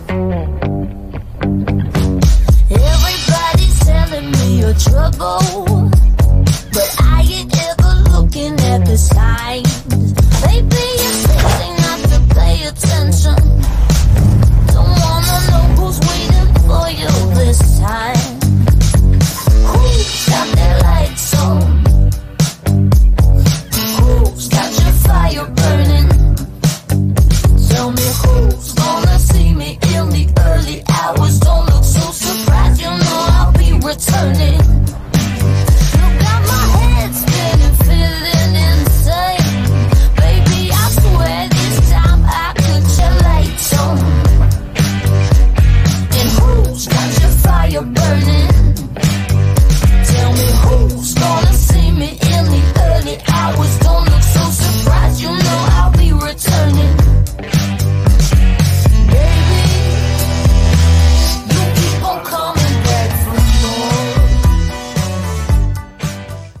• Качество: 183, Stereo
громкие
саундтреки
легкий рок